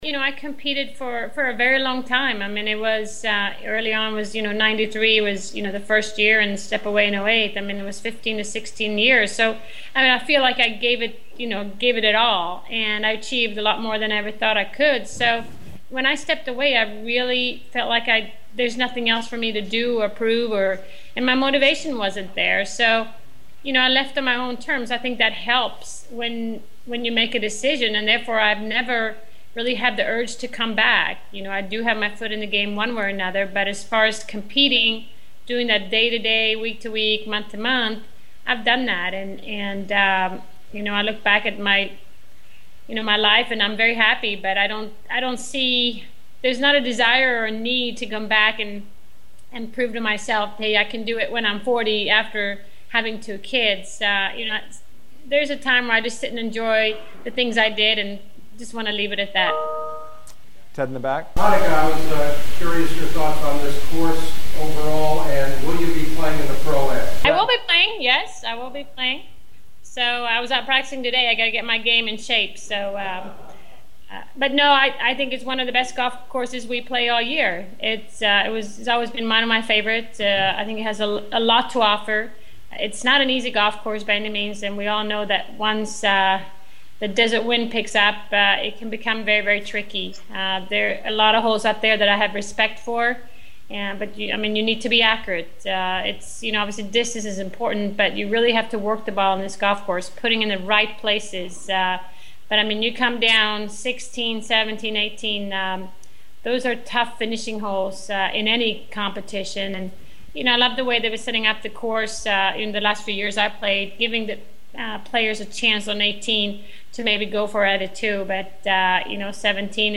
Annika was on Skype from her home in Florida and she’ll be this year’s Pro-Am host the week of April 1 and she talked about not being motivated to ever make a comeback to competitive golf and how she loves this course.